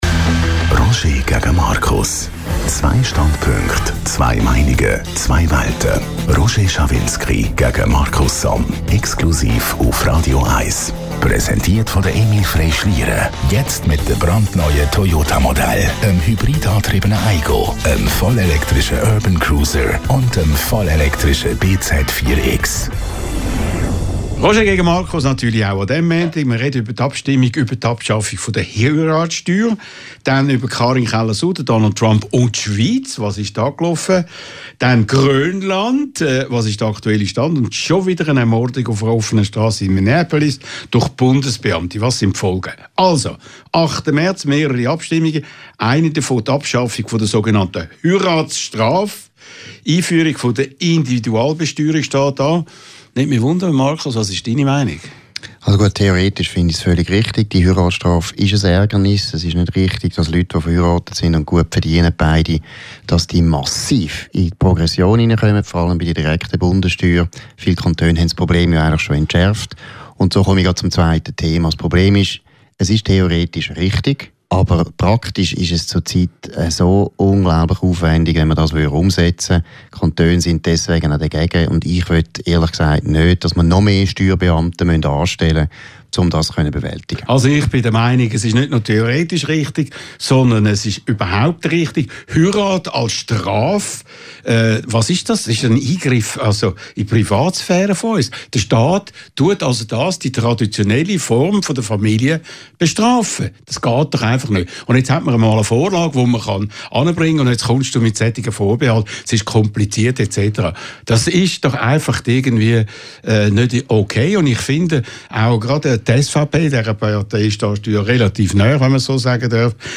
Publizist Markus Somm und Radio 1-Chef Roger Schawinski diskutieren in kontroverser Form über aktuelle Themen der Woche.